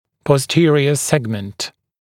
[pɔs’tɪərɪə ‘segmənt][пос’тиэриэ ‘сэгмэнт]дистальный отдел, боковой сегмент